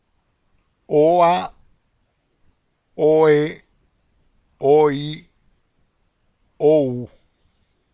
And finally, we are going to pronounce the combinations of two vowels (V-V):